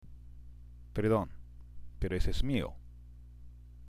＜発音と日本語＞
（ペルドン、ペロ　エソエス　ミオ）